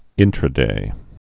(ĭntrə-dā)